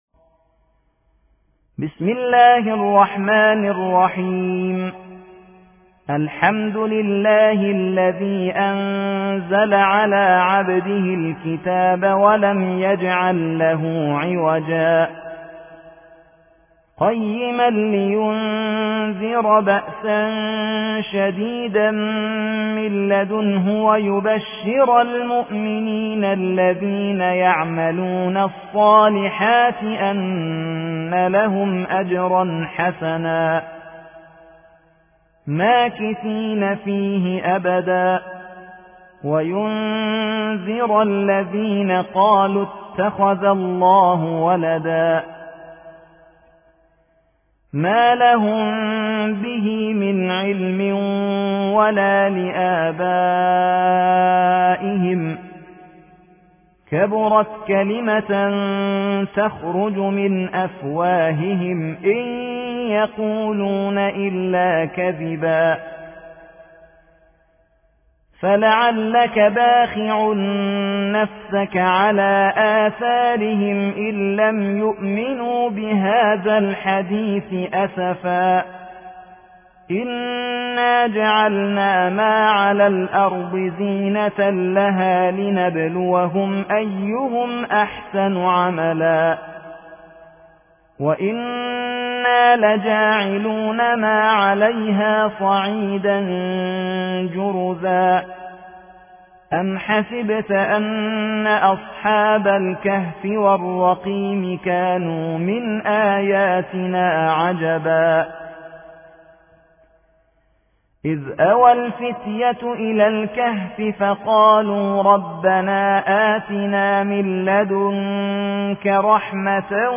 18. سورة الكهف / القارئ